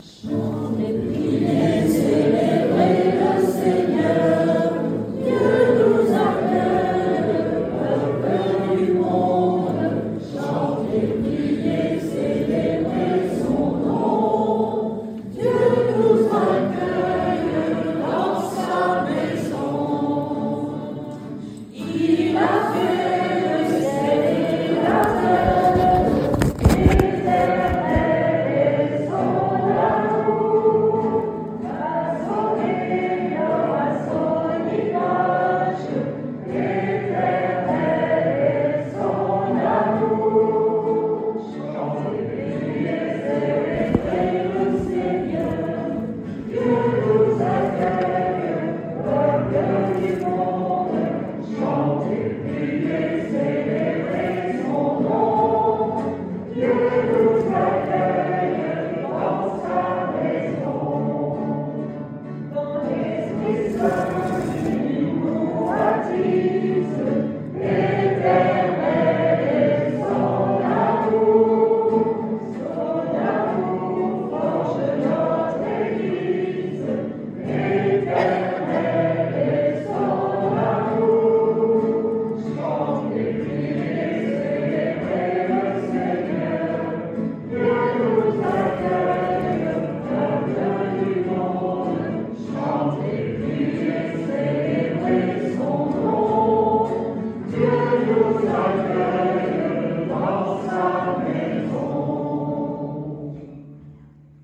La dernière messe
la messe de désacralisation
Audio : chant « Chantez, priez le Seigneur »